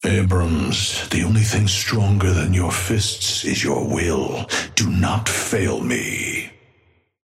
Patron_male_ally_atlas_start_03.mp3